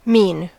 Ääntäminen
IPA : /miːn/